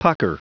Prononciation du mot : pucker